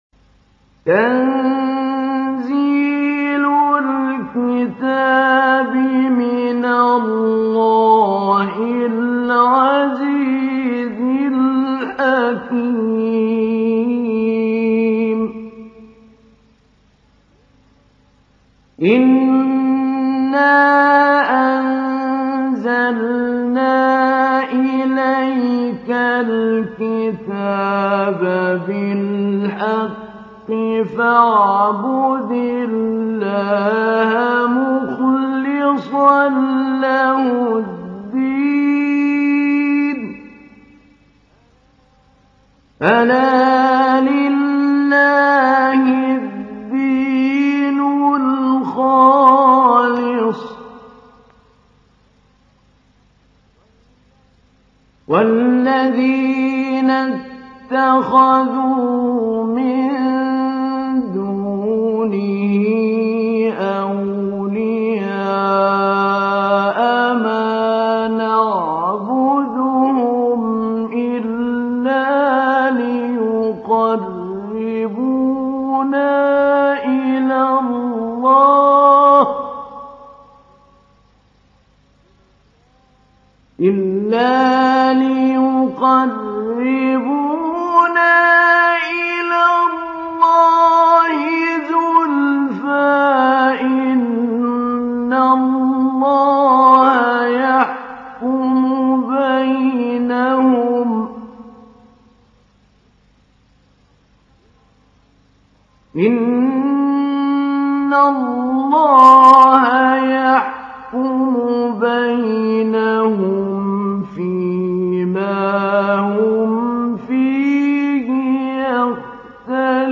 تحميل : 39. سورة الزمر / القارئ محمود علي البنا / القرآن الكريم / موقع يا حسين